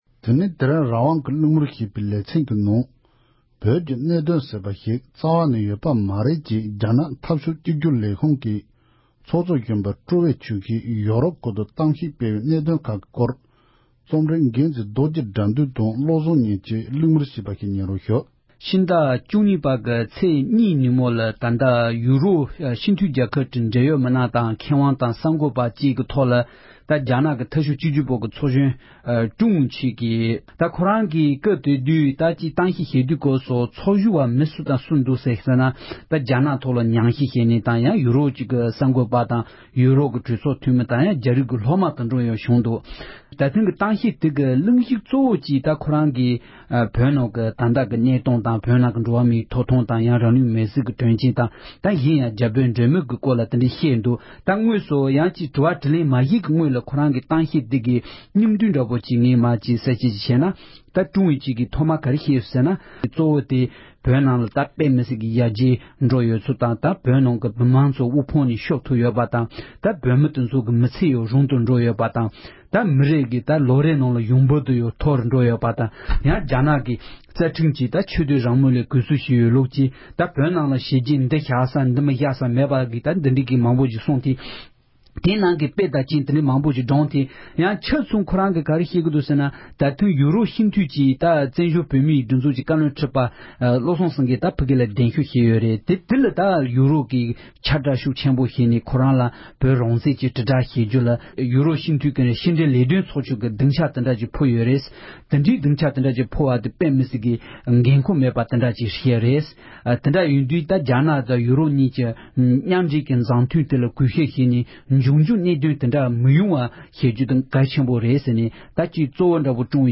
བཀའ་འདྲི་ཞུས་པར་གསན་རོགས་གནོངས༎